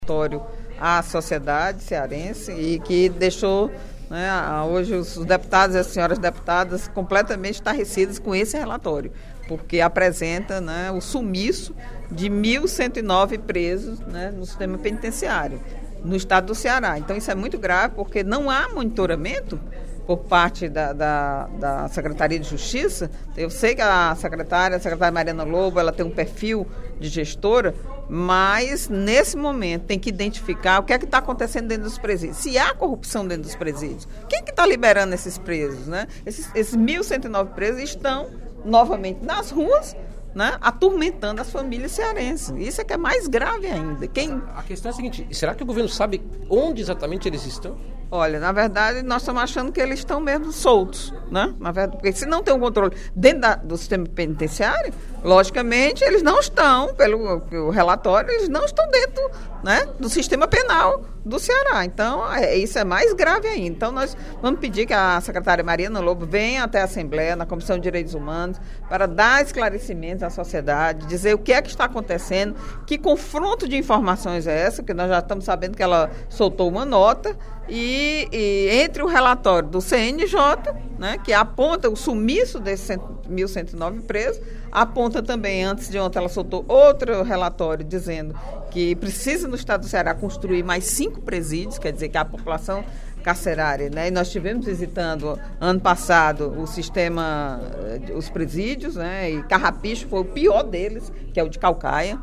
No primeiro expediente da sessão plenária desta sexta-feira (14/02), a deputada Eliane Novais (PSB) comentou sobre o desaparecimento de 1.109 presos da massa carcerária do Estado.